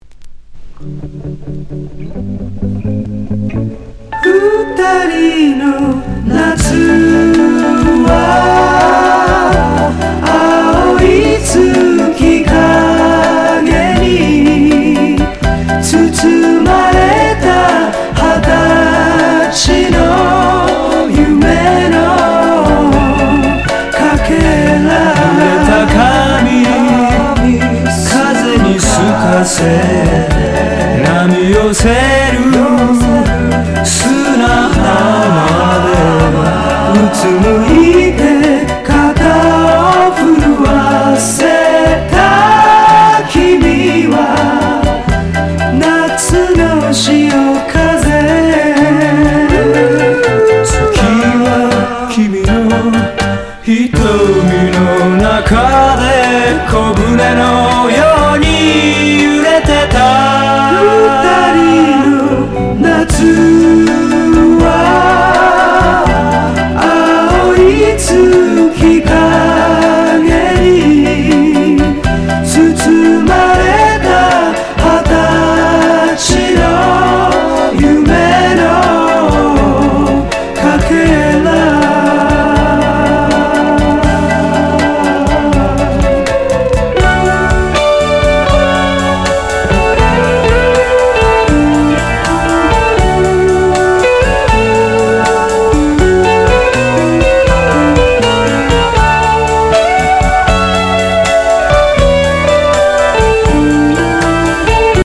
ビーチでボーイなサワー感
和製サーフ・バラード佳曲！